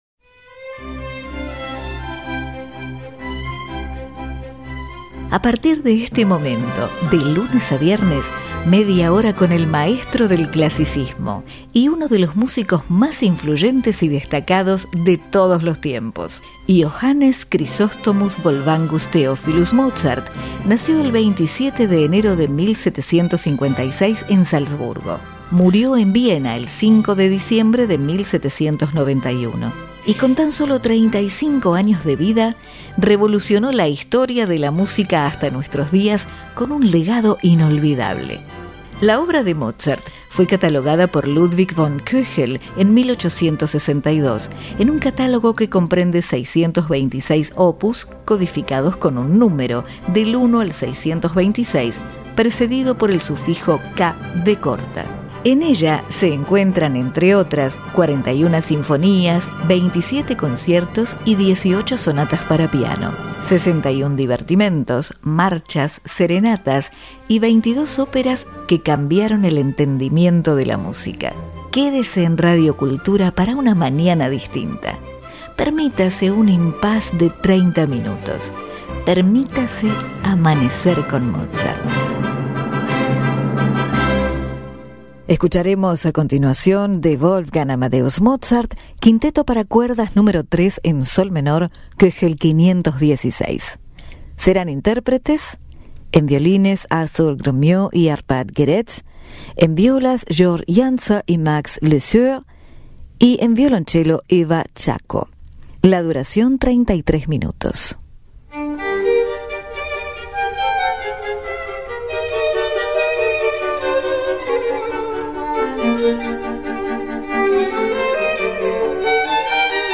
Arthur Grumiaux (Violin)
Violoncello
Violas